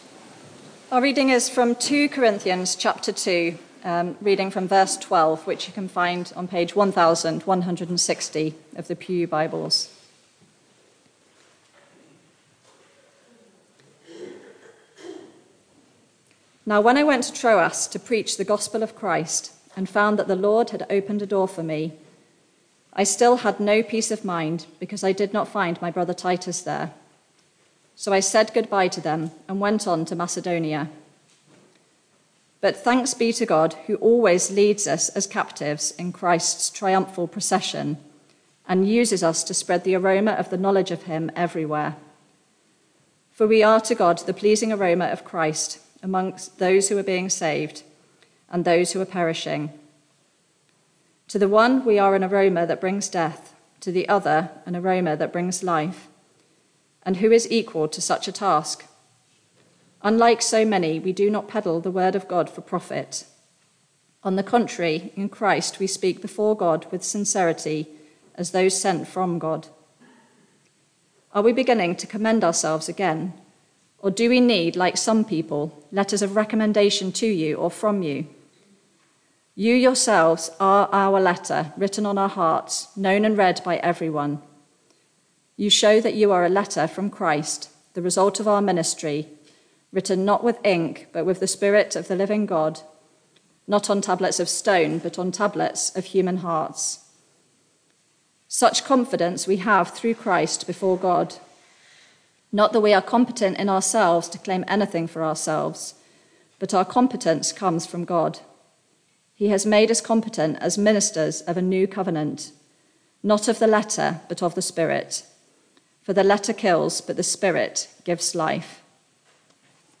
Media for Barkham Morning Service on Sun 01st Oct 2023 10:00
Sermon